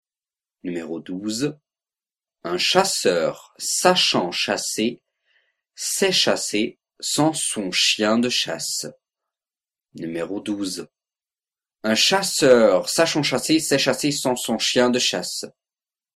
12 Virelangue